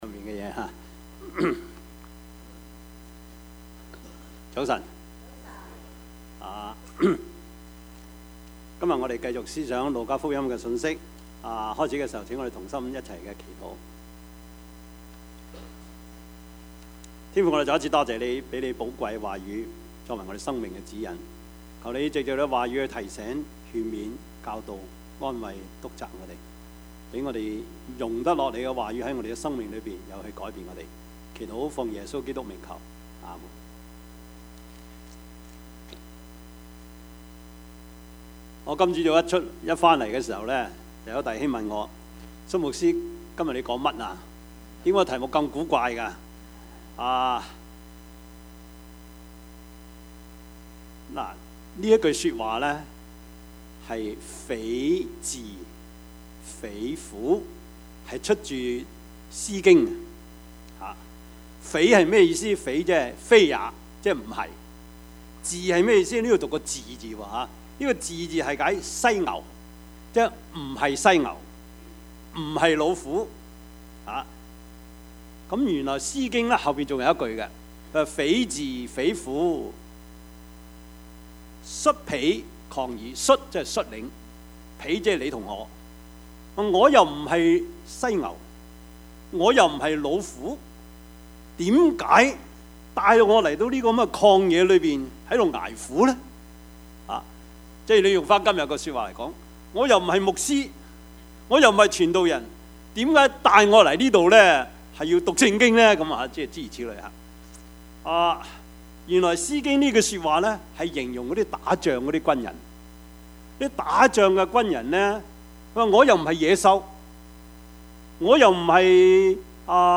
Service Type: 主日崇拜
Topics: 主日證道 « 罪如何進入世界?